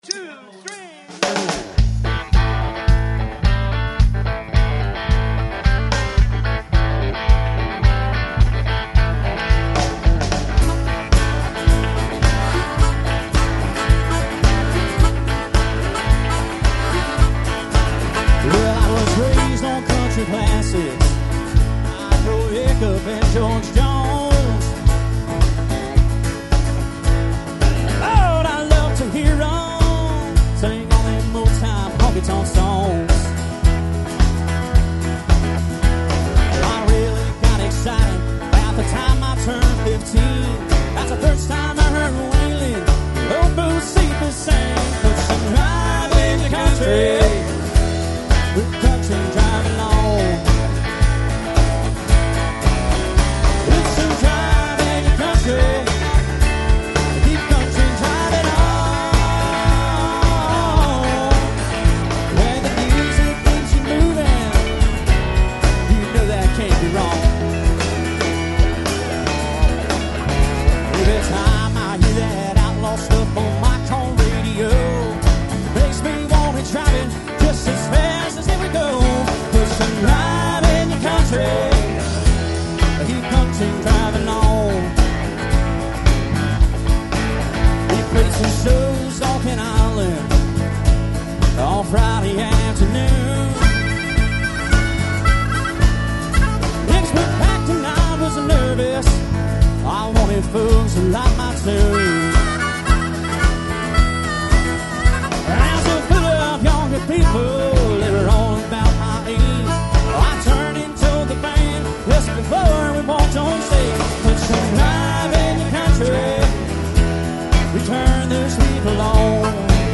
Live :: Location :: Mobile :: Recording
country